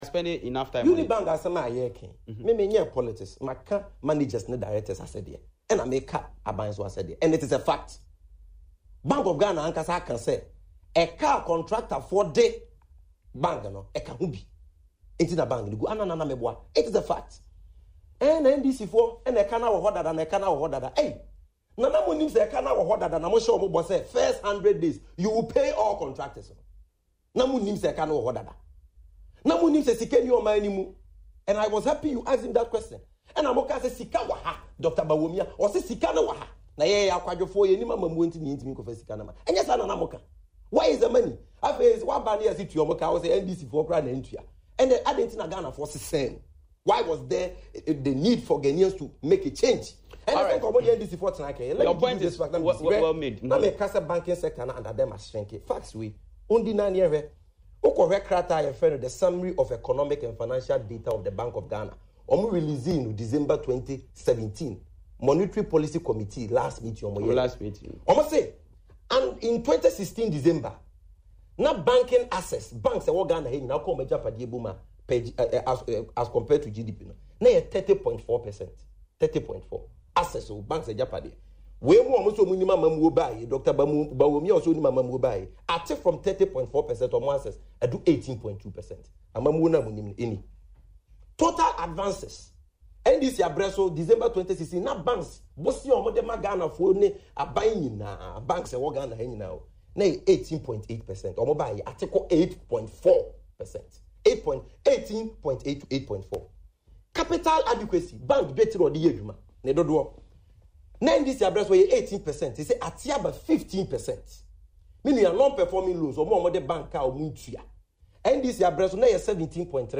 But reacting to the issue on Adom TV’s Morning Show “Badwam” Wednesday